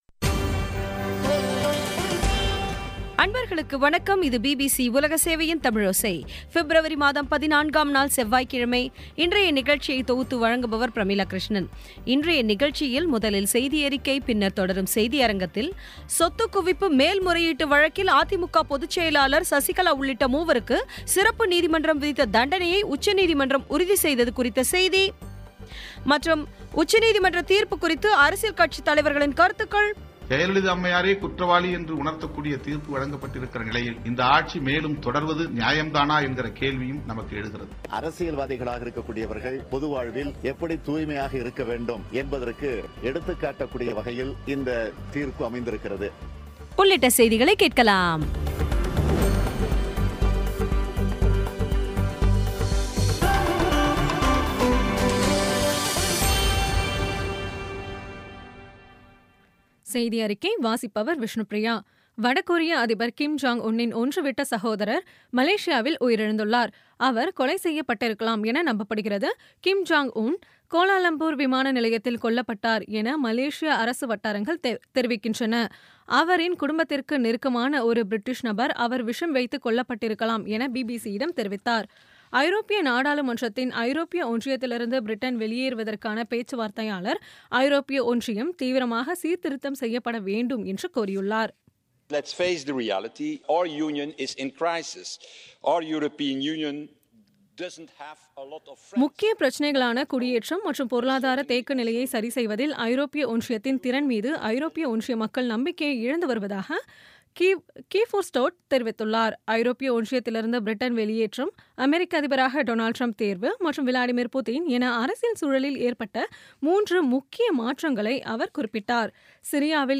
இன்றைய தமிழோசையில், முதலில் செய்தியறிக்கை, பின்னர் தொடரும் செய்தியரங்கத்தில், சொத்துக் குவிப்பு மேல்முறையீட்டு வழக்கில், அதிமுக பொது செயலாளர் சசிகலா உள்ளிட்ட மூவருக்கு சிறப்பு நீதிமன்றம் விதித்த தண்டனையை உச்சநீதிமன்றம் உறுதி செய்தது குறித்த உச்சநீதிமன்ற தீர்ப்பு குறித்து அரசியல் கட்சிதலைவர்களின் கருத்துக்கள் ஆகியவை கேட்கலாம்